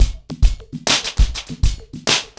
PrintOuts-100BPM.9.wav